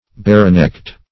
Barenecked \Bare"necked`\, a. Having the neck bare.